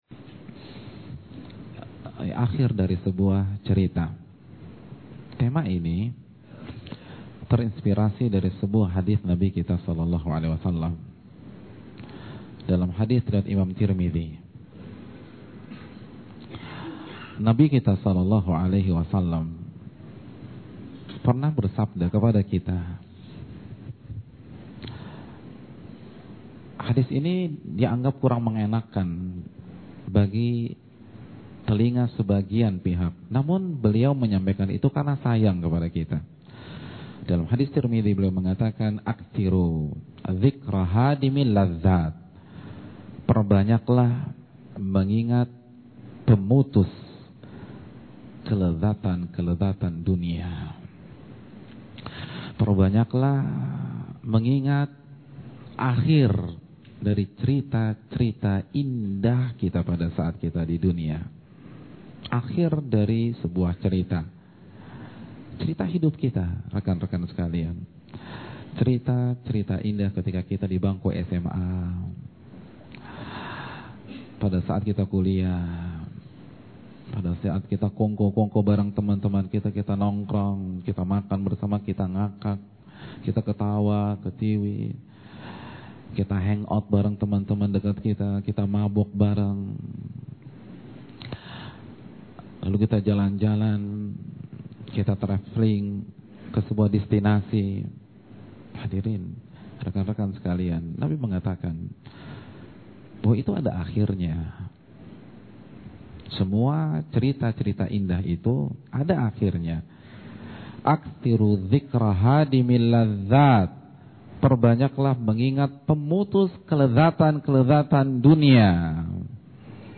Jangan lewatkan kajian menarik berikut ini dengan tema “AKHIR SEBUAH CERITA”…